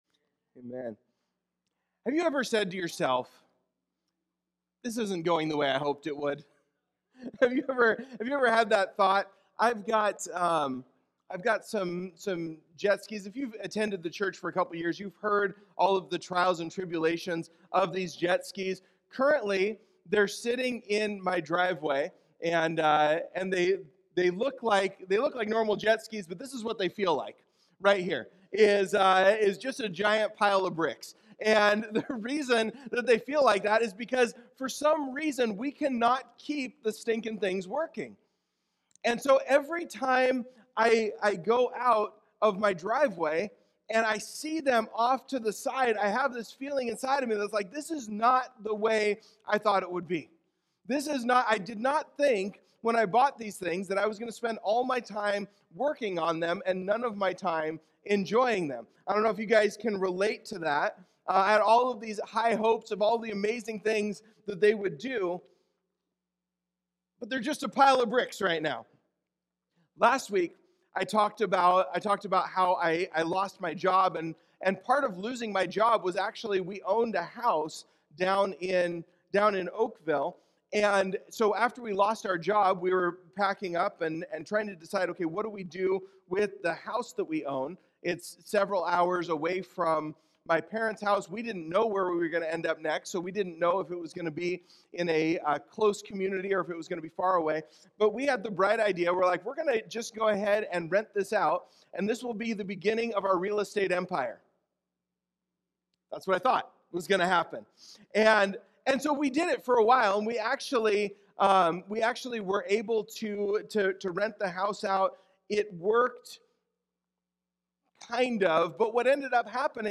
In this sermon we’ll discover how the empty tomb proves that Jesus didn’t leave us alone, and that He wants to be with us in the storms.